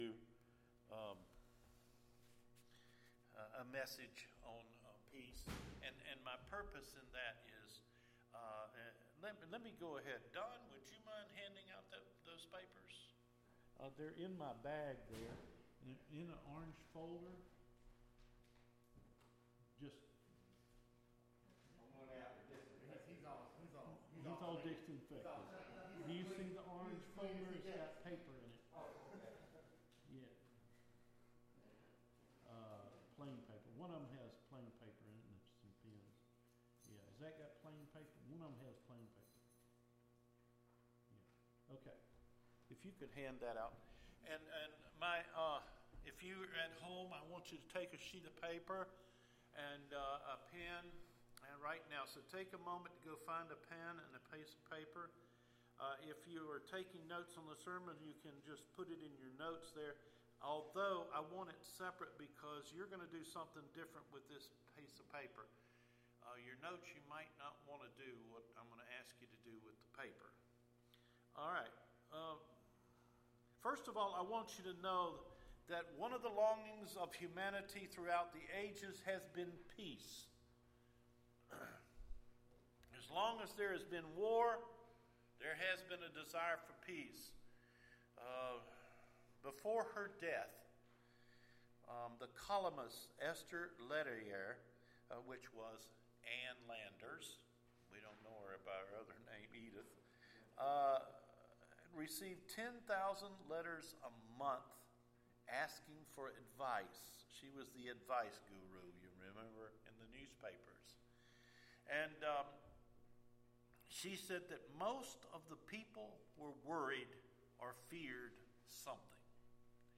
RESTING IN HIS PEACE – OCTOBER 11 SERMON – Cedar Fork Baptist Church